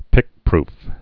(pĭkprf)